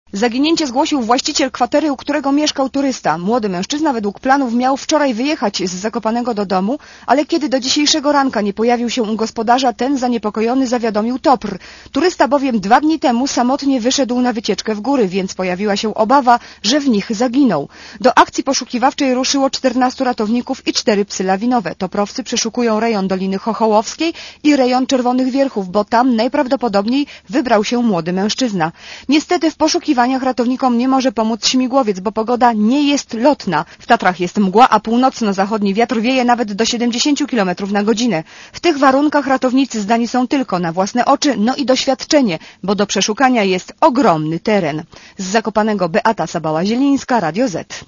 Relacja reportera Radia ZET Młody mężczyzna wyszedł w góry w sylwestra.